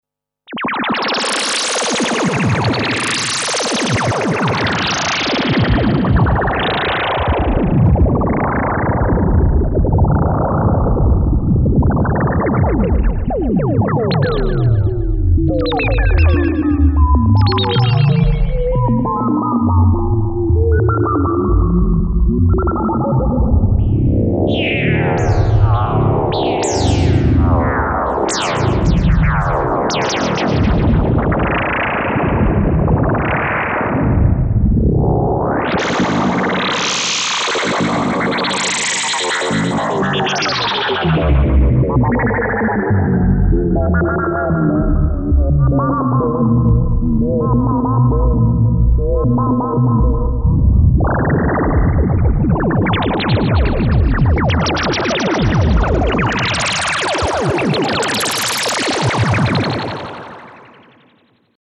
Трезвенники ... блин ... а я тут до АРПа дорвался с пьяных глаз (баба орёёёт, типа громка), а я Джеймс Бонд и ниипёт Гыы, посмотрел бы я как это сделать на Вирусе ... при условии что у тебя 1 осциллятор и один фильтр ... Кстати 1-е 20 секунд звучит голый фильтр безничего Вложения arp01.mp3 arp01.mp3 2,4 MB · Просмотры: 388